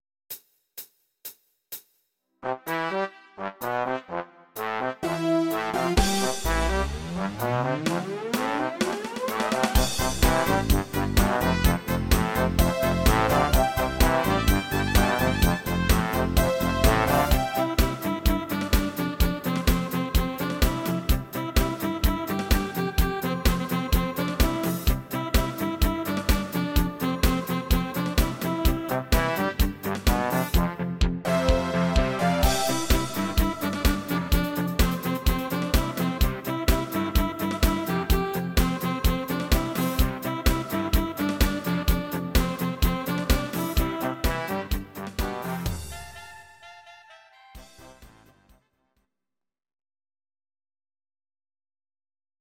These are MP3 versions of our MIDI file catalogue.
Please note: no vocals and no karaoke included.
Your-Mix: Volkstï¿½mlich (1262)